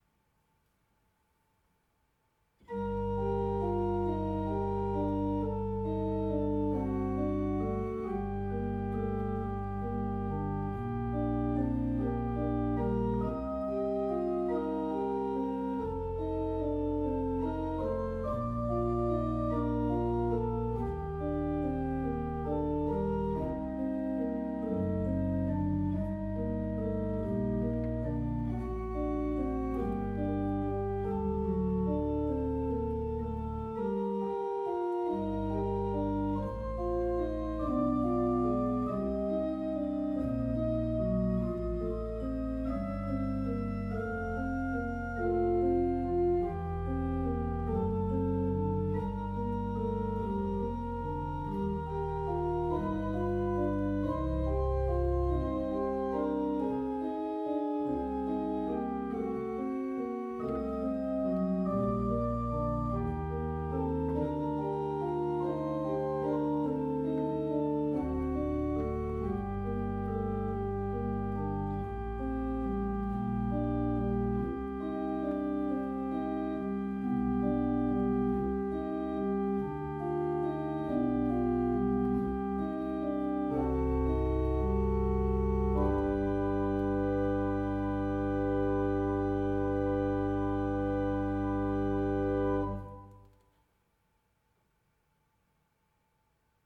Orgel
Unsere Orgel (Orgel-Mayer, Heusweiler) wurde im Sommer 2012 durch die Orgelbaufirma Förster & Nicolaus (Lich) gründlich gereinigt und repariert und hat einen vollen und harmonischen Klang.